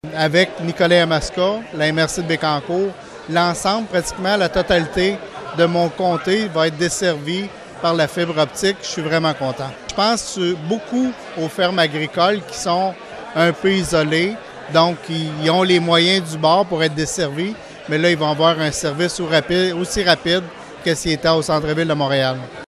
L’annonce de la contribution gouvernementale, provenant du programme Québec Branché, a été annoncée par le député de Nicolet-Bécancour, Donald Martel, qui avait fait de cet enjeu une priorité :